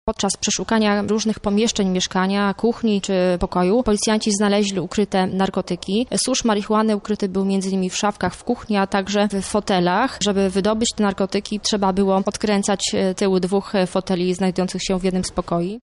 -mówi aspirant